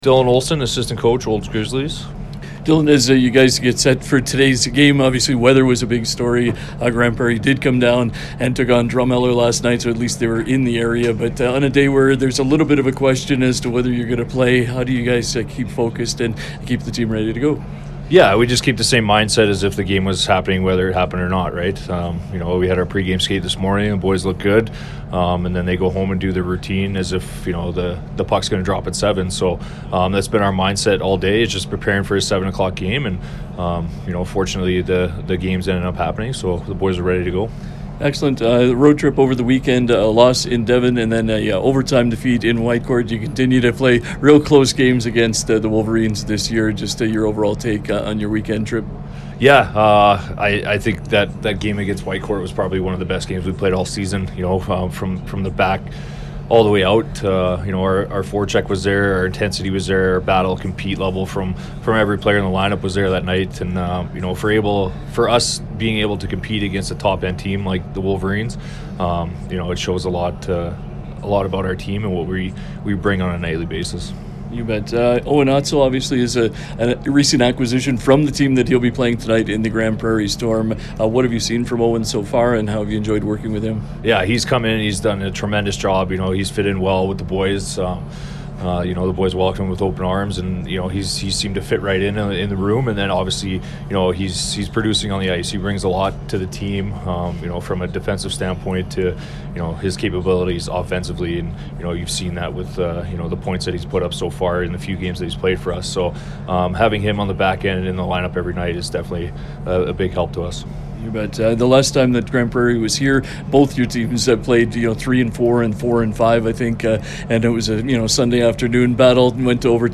pre-game conversation